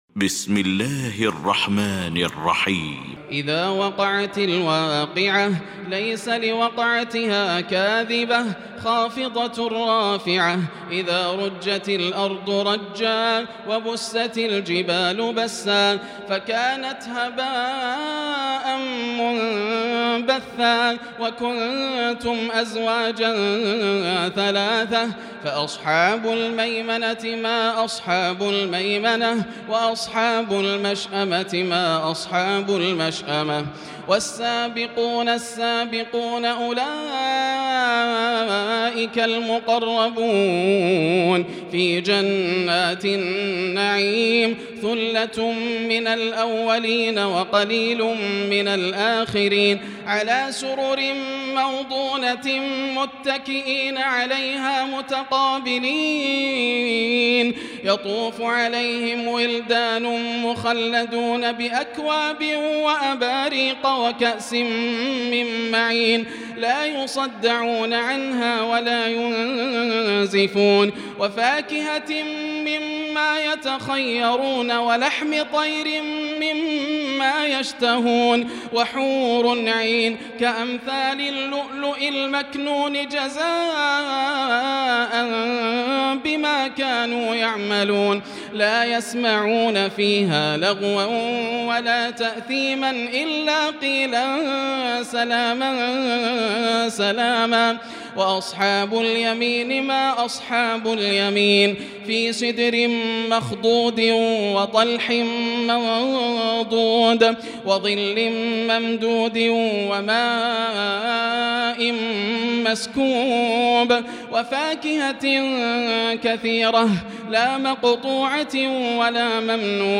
المكان: المسجد الحرام الشيخ: فضيلة الشيخ ياسر الدوسري فضيلة الشيخ ياسر الدوسري الواقعة